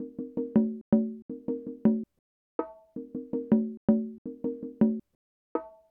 • bongos and congas resample 3.wav
bongos_and_congas_sample_3_CV8.wav